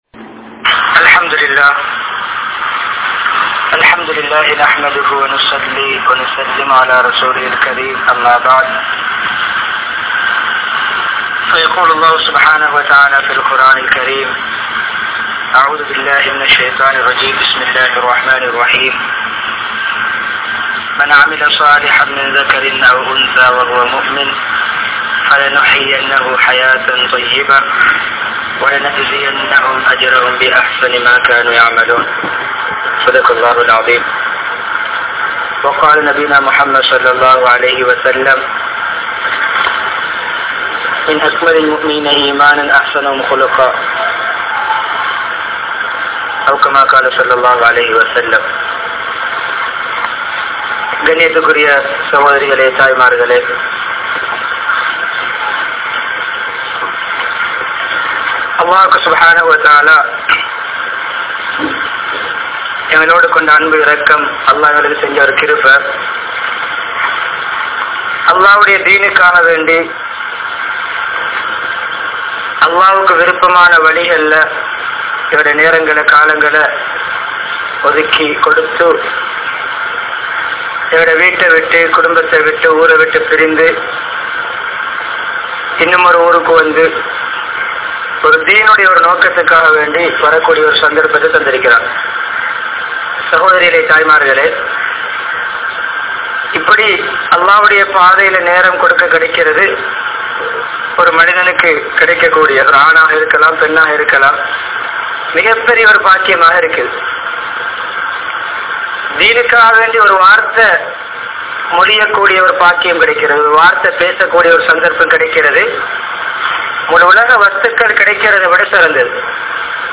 Thooya Pengalin Panphal (தூய பெண்களின் பண்புகள்) | Audio Bayans | All Ceylon Muslim Youth Community | Addalaichenai
Salihath Jumua Masjidh